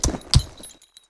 horses
walk2.wav